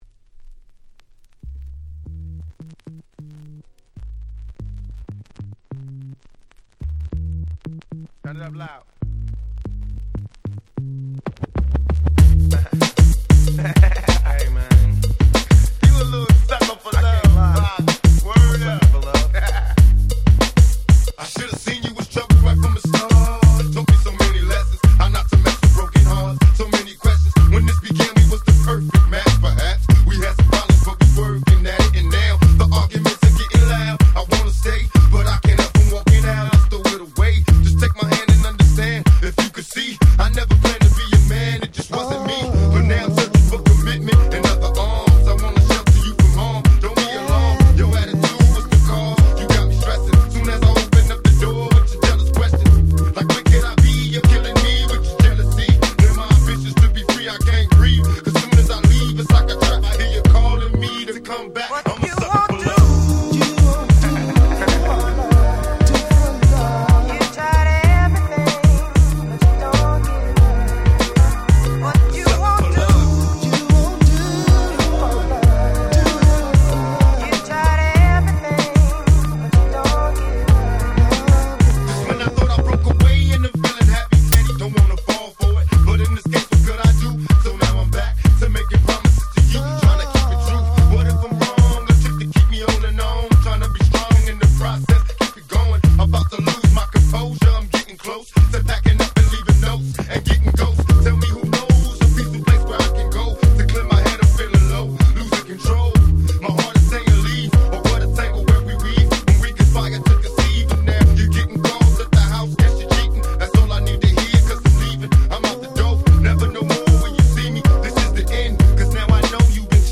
West Coast Hip Hop Classic !!